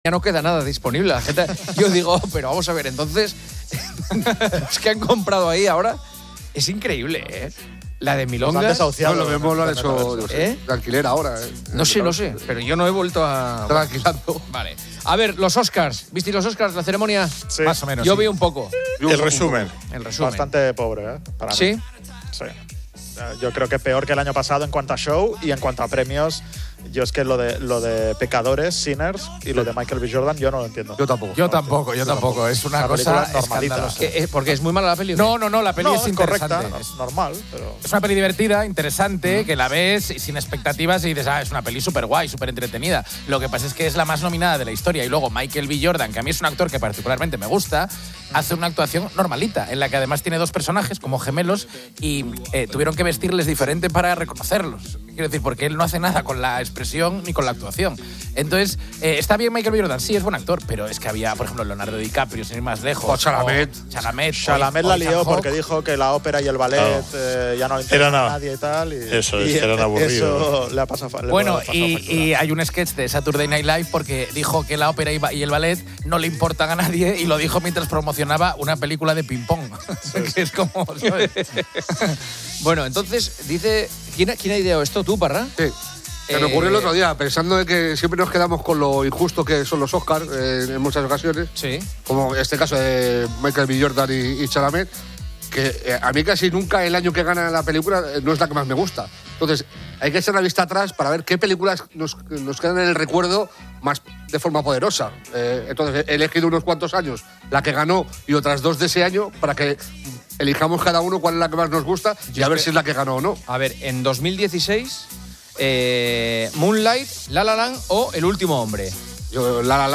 El programa critica la última ceremonia de los Oscar por su baja calidad y nominaciones cuestionables. Los tertulianos debaten qué películas debieron ganar en años anteriores, comparando opciones como "La La Land" o "Moonlight", "La Red Social" o "El Discurso del Rey", y "Pulp Fiction" o "Forrest Gump". A continuación, presentan la lista de actores y actrices mejor pagados de 2025.